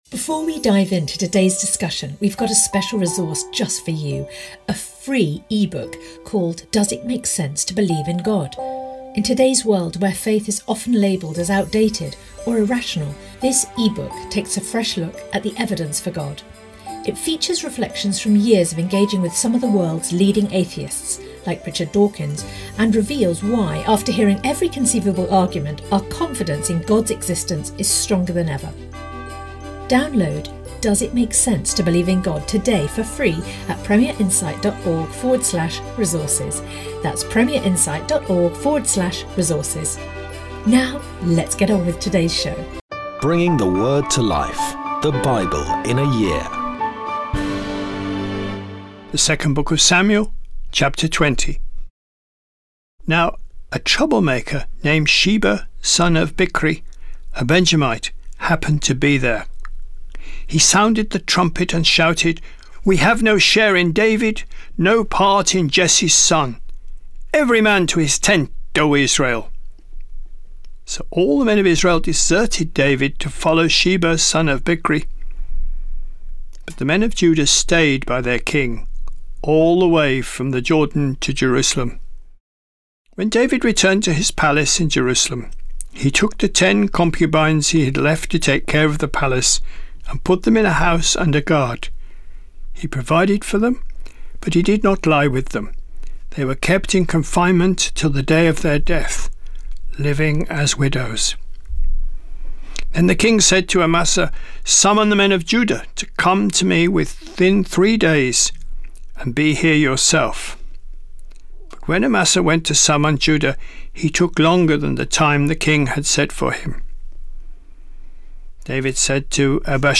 Today's reading comes from 2 Samuel 20-21; 1 Corinthians 1